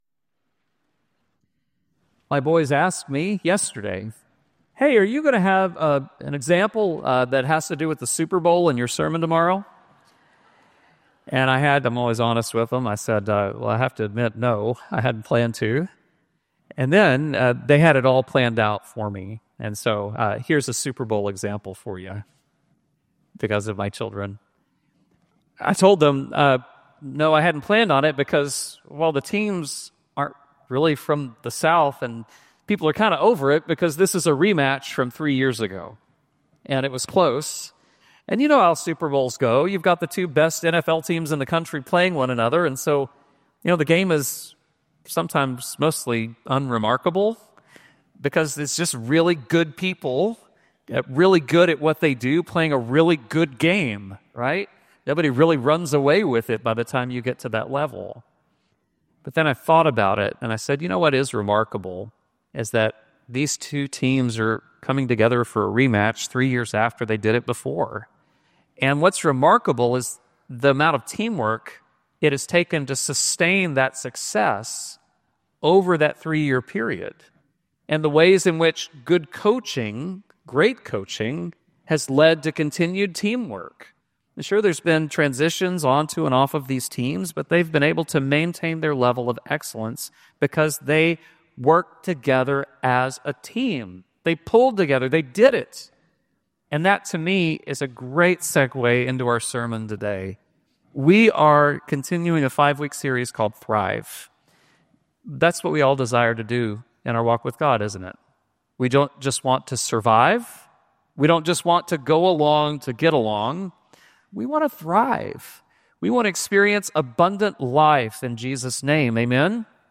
Service Type: Traditional